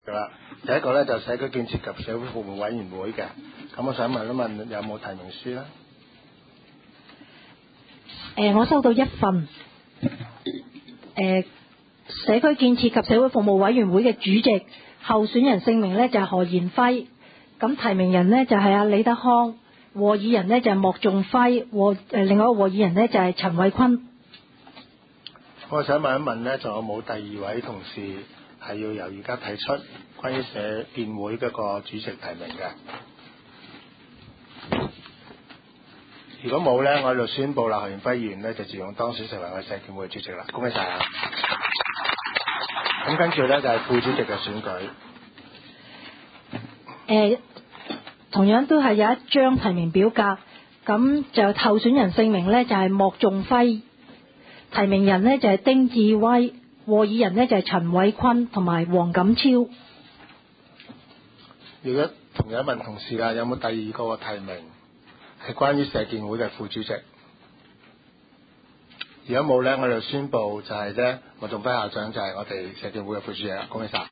委员会会议的录音记录
社区建设及社会服务委员会第一次会议 日期: 2012-01-17 (星期二) 时间: 下午2时30分 地点: 九龙黄大仙龙翔道138号 龙翔办公大楼6楼 黄大仙区议会会议室 议程 讨论时间 I 选举委员会主席及副主席 0:01:14 全部展开 全部收回 议程:I 选举委员会主席及副主席 讨论时间: 0:01:14 前一页 返回页首 如欲参阅以上文件所载档案较大的附件或受版权保护的附件，请向 区议会秘书处 或有关版权持有人（按情况）查询。